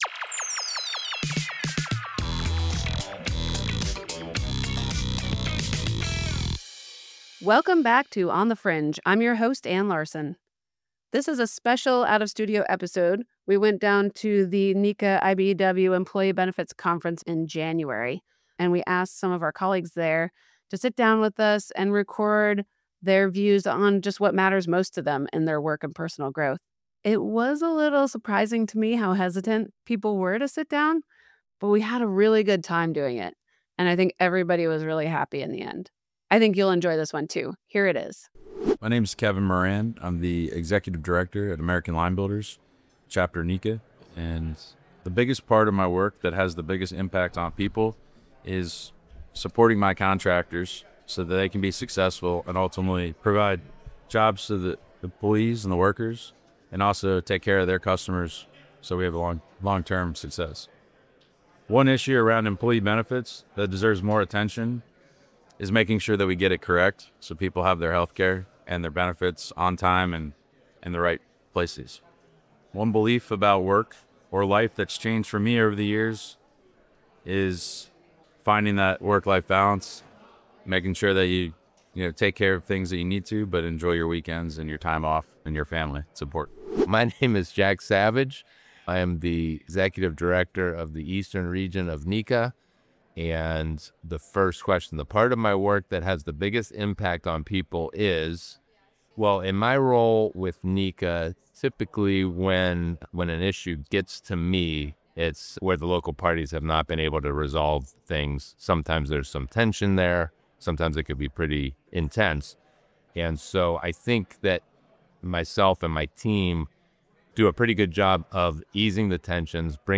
Ep. 17 - Union Healthcare, Mental Health & Benefits: Live from IBEW-NECA EBC 2026